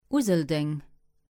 Useldange (Luxembourgish: Useldeng [ˈuzəldeŋ]